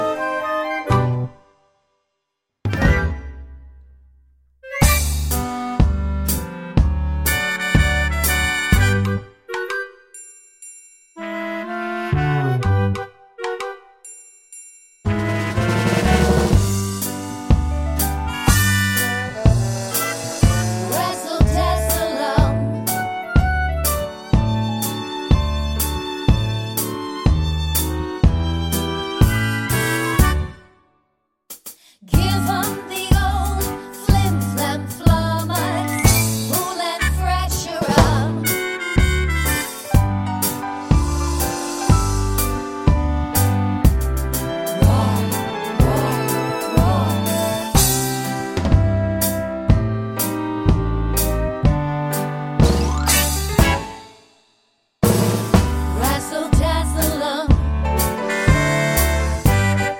no Backing Vocals Musicals 3:46 Buy £1.50